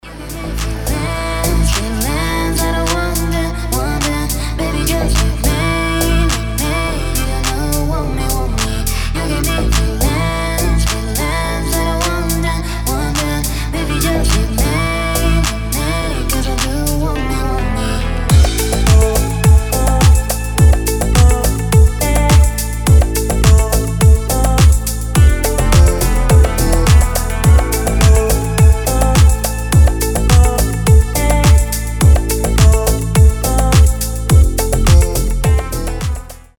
• Качество: 320, Stereo
deep house
басы
чувственные
красивый женский голос
Стиль: deep house